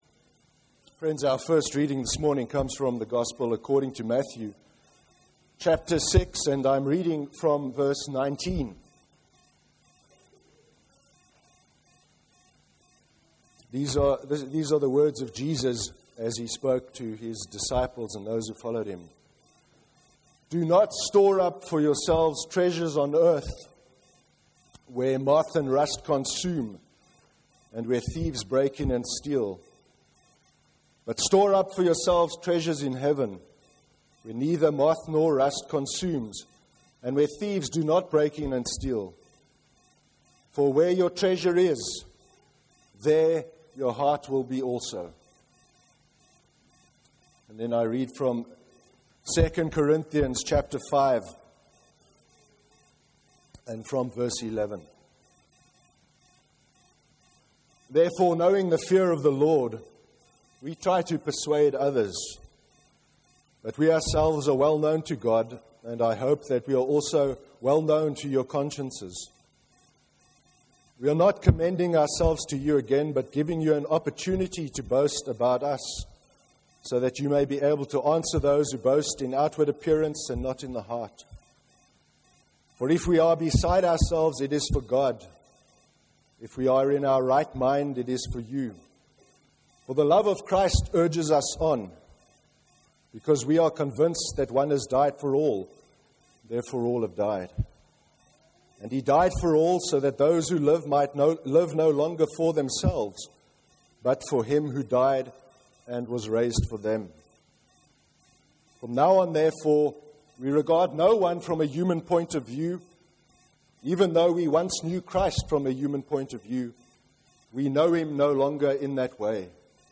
19-1-14-sermon.mp3